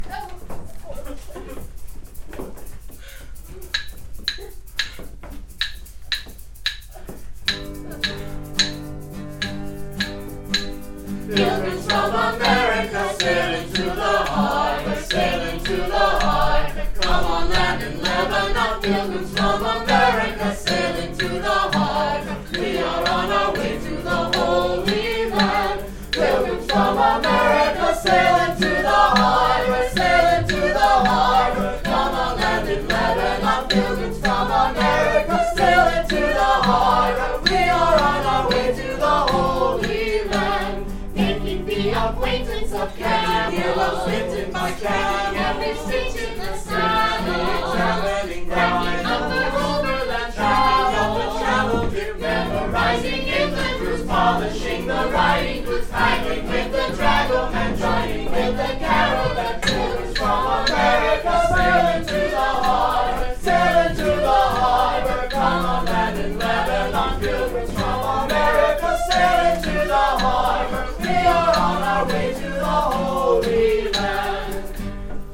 The song is lighthearted, for the most part.